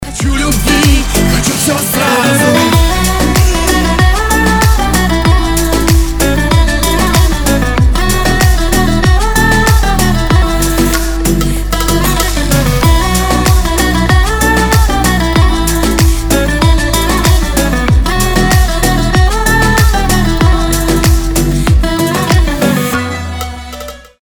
поп
восточные мотивы
эстрадные